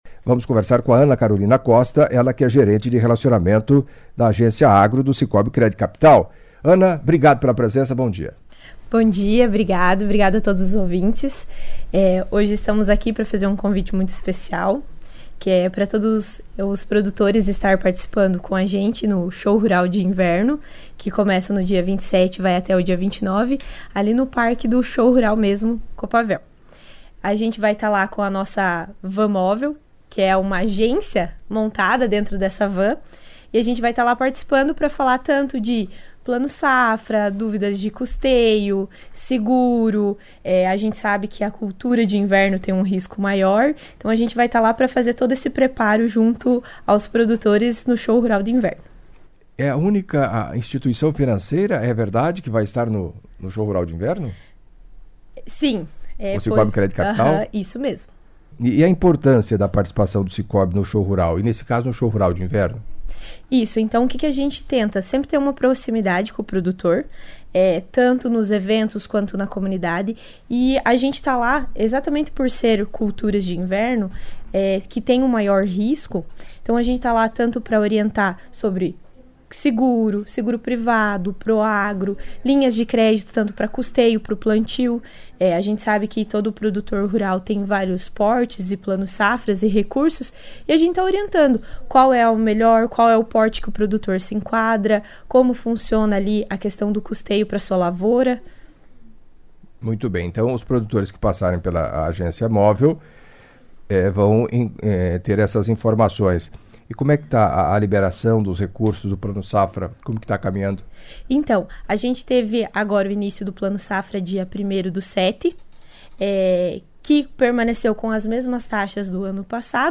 Em entrevista à CBN Cascavel nesta quarta-feira (21)